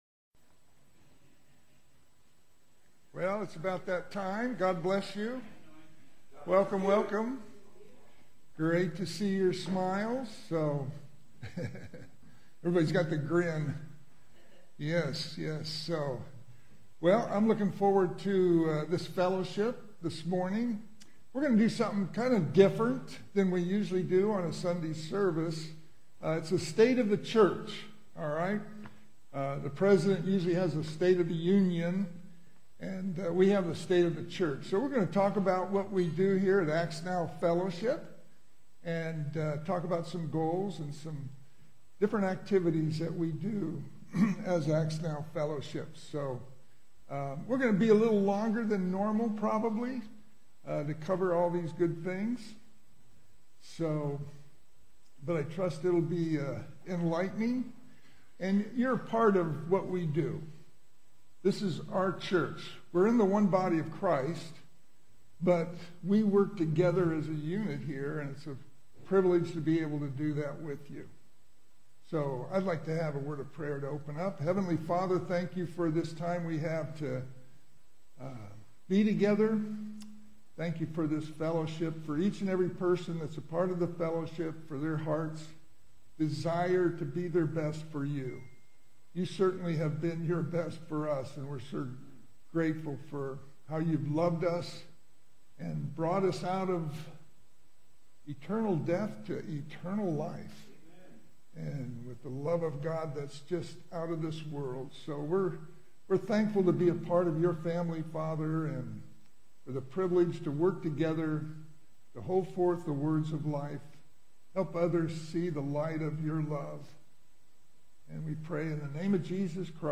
4 Essentials for Effective Prayers and The State of the Church Details Series: Conference Call Fellowship Date: Sunday, 14 September 2025 Hits: 152 Scripture: 1 Thessalonians 5:15-18 Play the sermon Download Audio ( 75.26 MB )